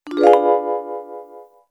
Confused.wav